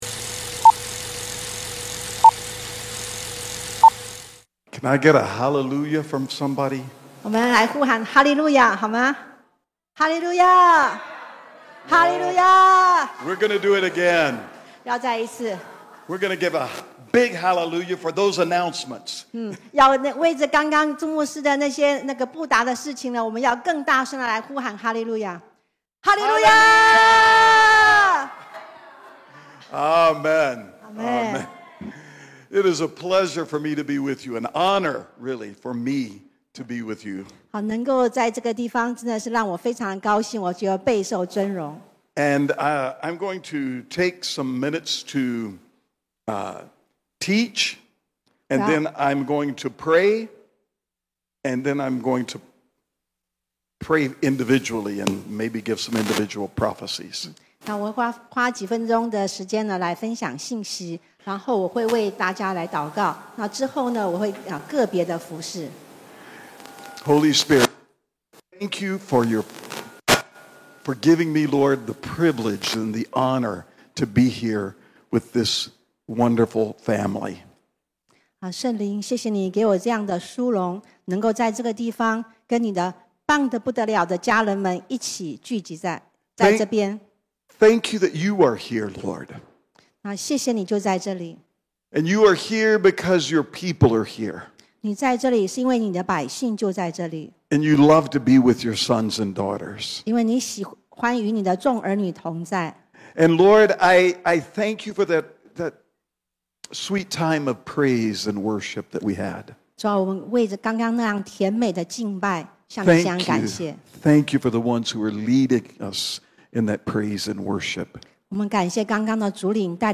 地點：台南CPE領袖學院